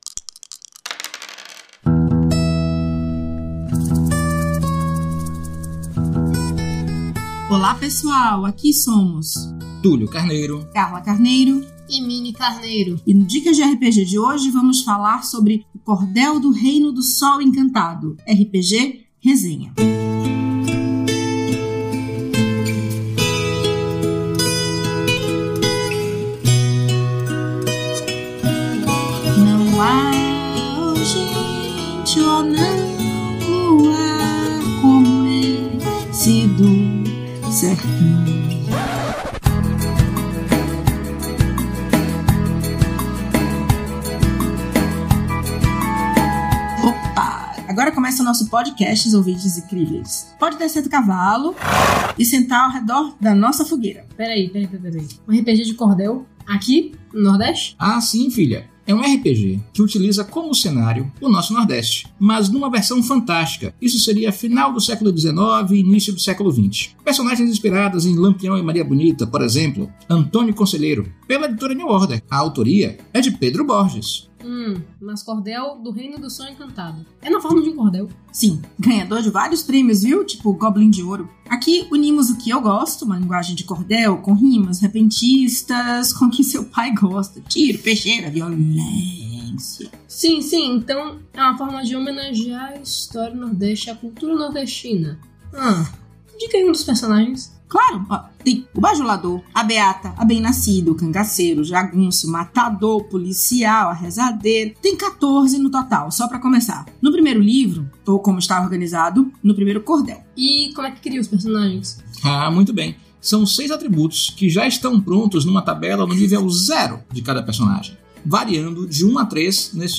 No Dicas de RPG de hoje, falamos sobre o Reino do Sol Encantado. Se aprochegue, e curta nossa resenha com cuscuz e a sanfona.
Músicas: Music by from Pixabay